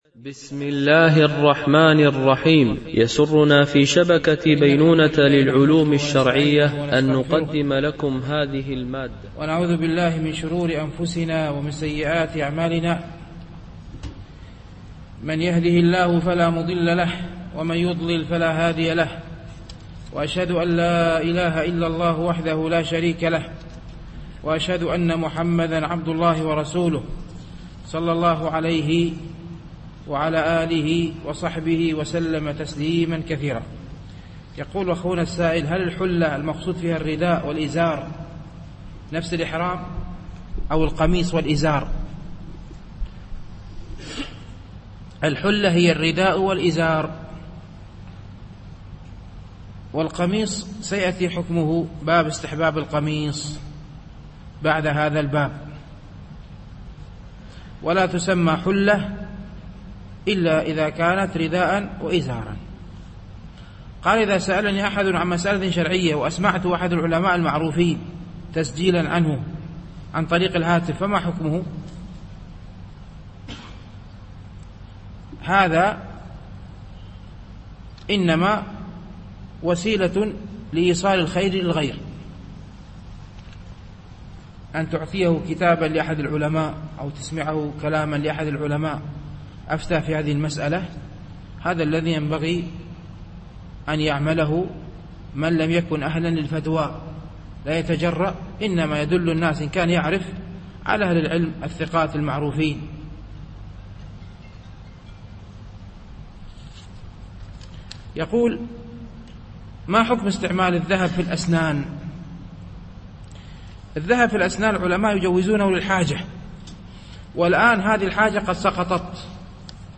شرح رياض الصالحين - الدرس 212 ( كتاب اللباس، باب 117: باب استحباب الثوب الأبيض وجواز الأحمر والأخضر والأصفر والأسود... ، الحديث 786 - 789 ) الألبوم: شبكة بينونة للعلوم الشرعية التتبع: 212 المدة: 44:38 دقائق (10.26 م.بايت) التنسيق: MP3 Mono 22kHz 32Kbps (CBR)